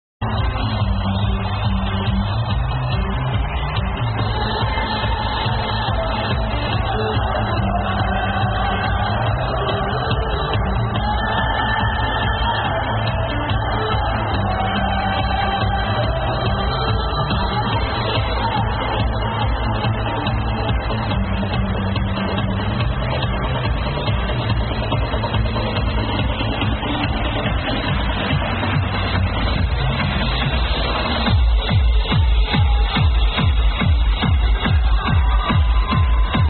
This is second track on whole liveset ^_^
sounds like its a remix